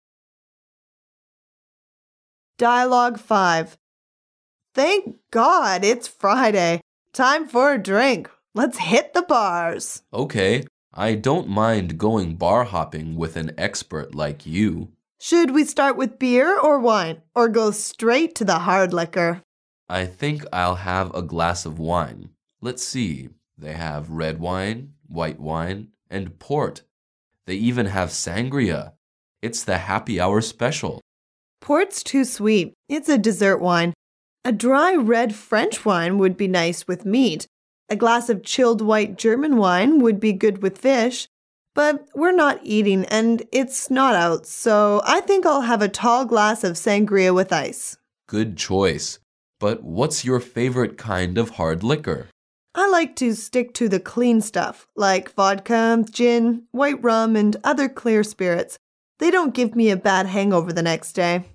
Dialouge 5